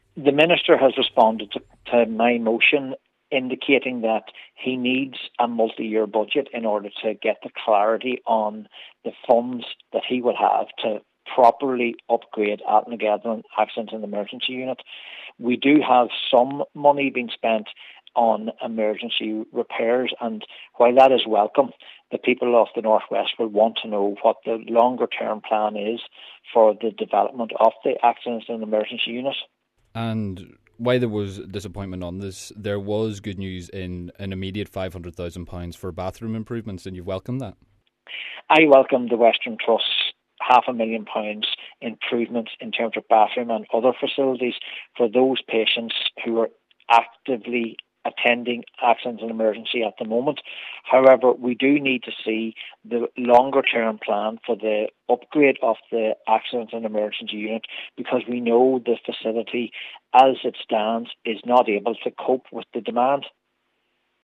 SDLP Cllr Martin Reilly says the people of the north west need to know what is going on